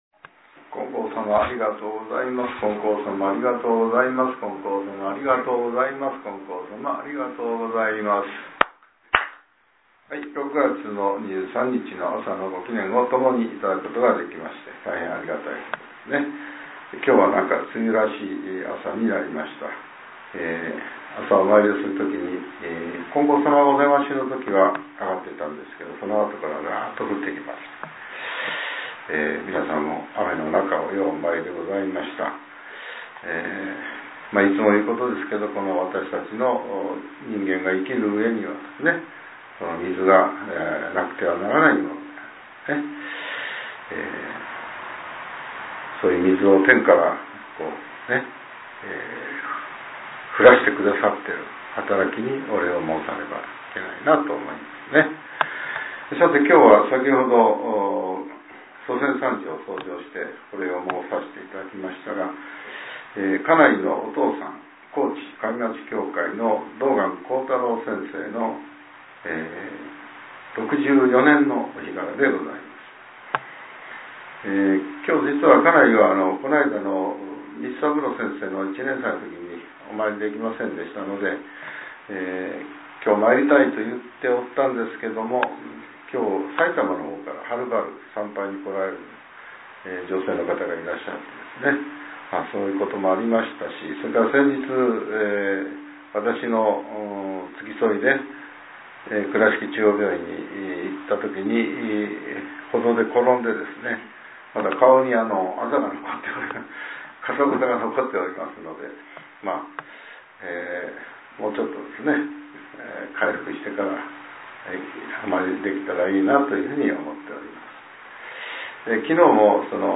令和７年６月２３日（朝）のお話が、音声ブログとして更新されています。